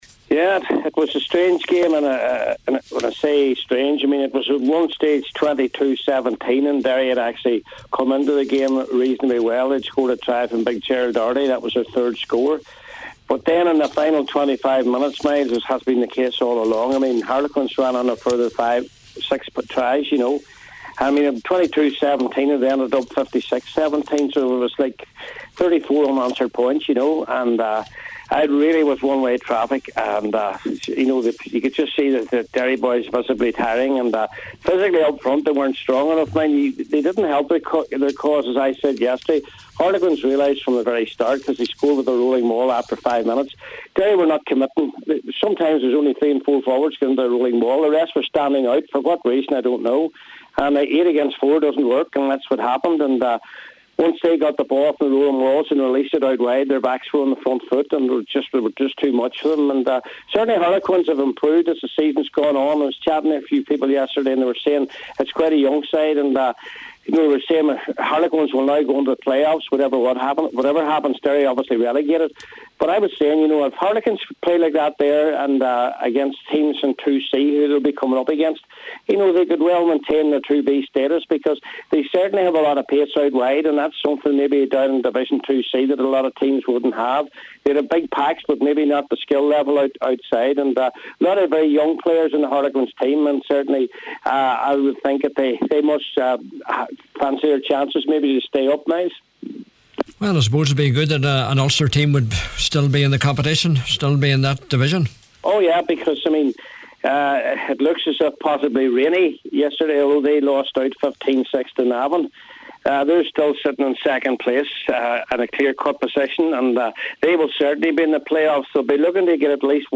reviewed the action on Sunday Sport…